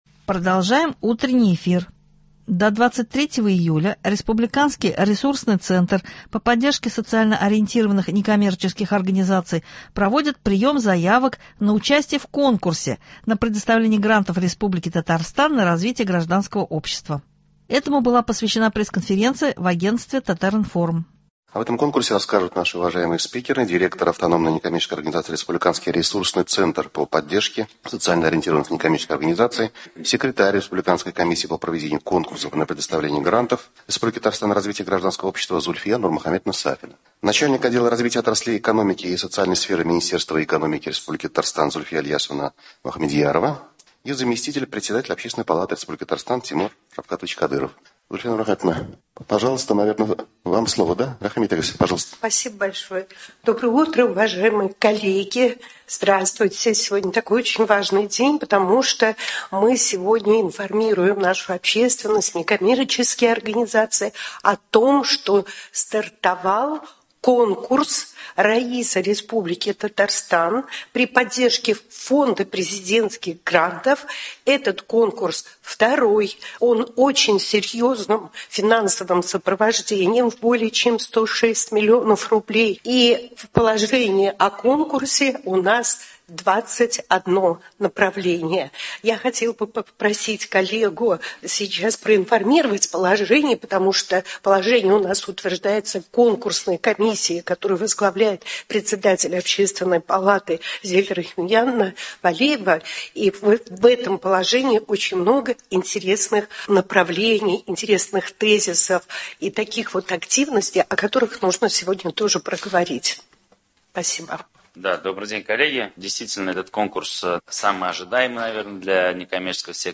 Особое внимание уделяется пожарной безопасности на полях – об этом рассказал в нашей студии зам мин с.х и прод. РТ Рафаэль Фаттахов.